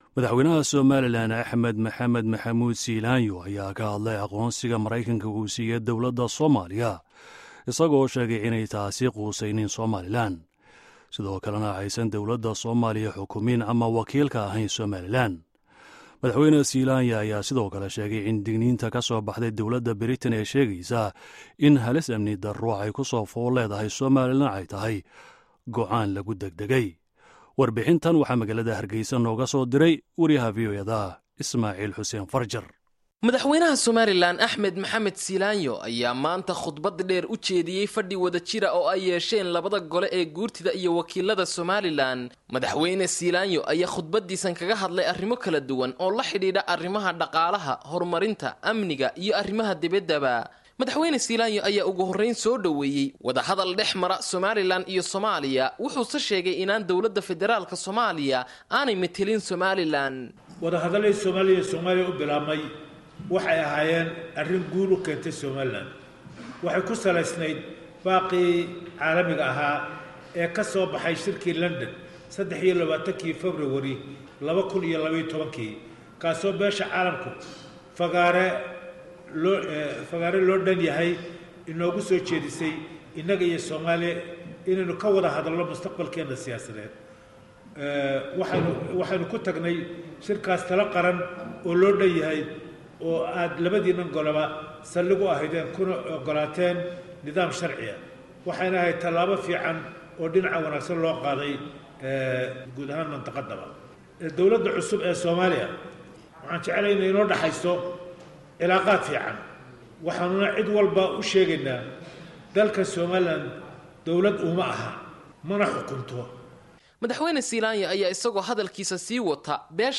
Hadalka Madaxweyne Siilaanyo iyo Wareysiga Matt Baugh